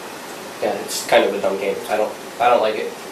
its kind of a dumb game i dont like it Meme Sound Effect
This sound is perfect for adding humor, surprise, or dramatic timing to your content.